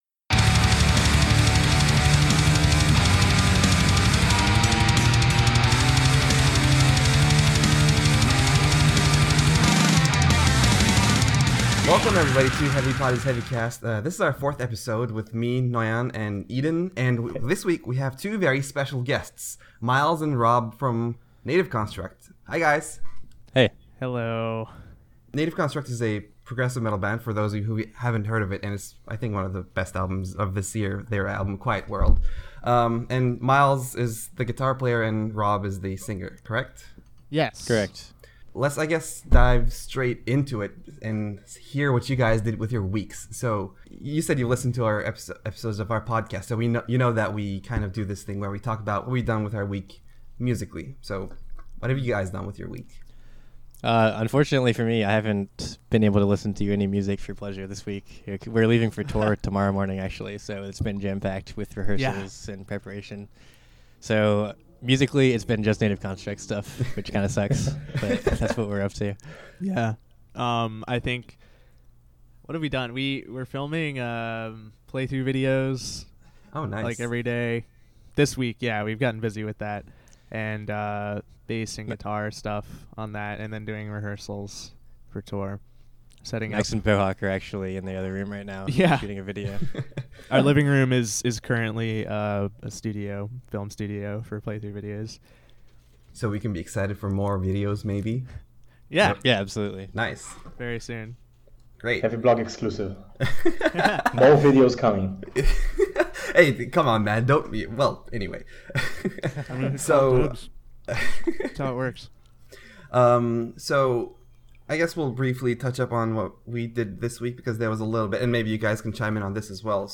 Four episodes into Heavy Pod Is Heavy Cast, and we have our first guests!